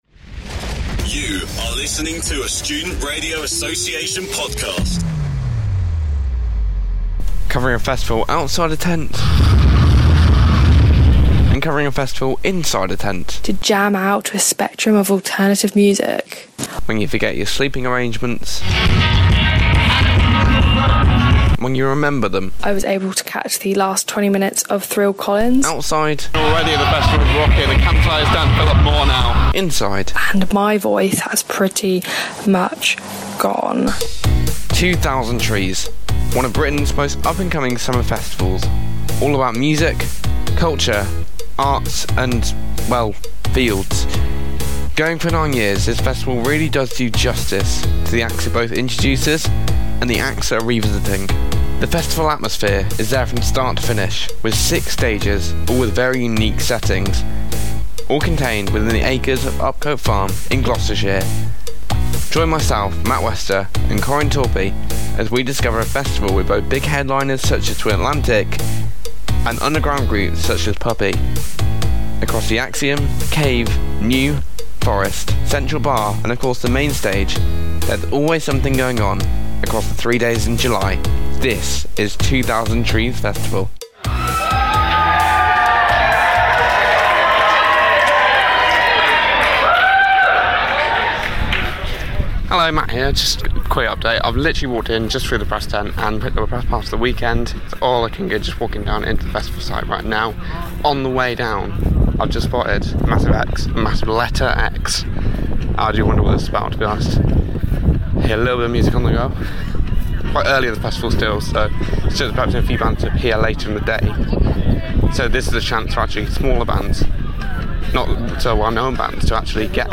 Rocking out at 2000 Trees: Best of Student Radio (July 2016)